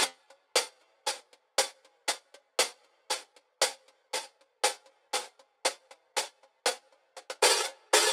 Lucky Hihat Loop.wav